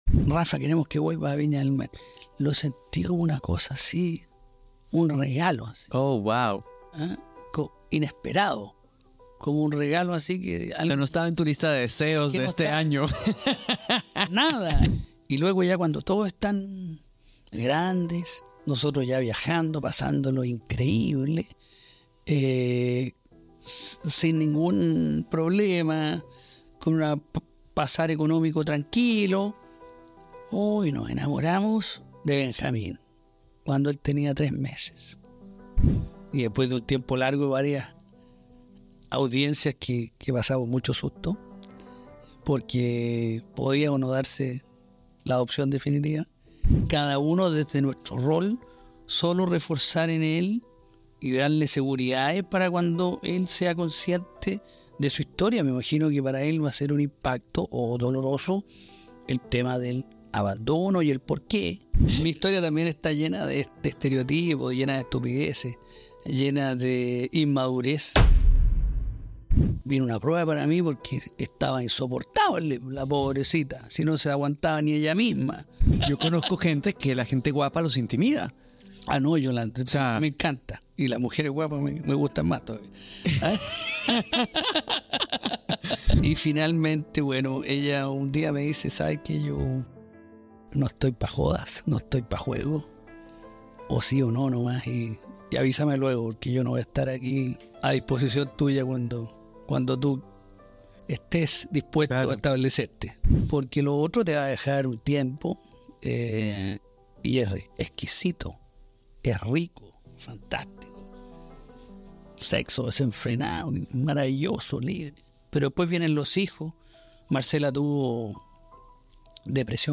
En este emocionante episodio de Chaban Podcast, Alejandro Chabán recibe al reconocido presentador de televisión chileno, Rafael Araneda.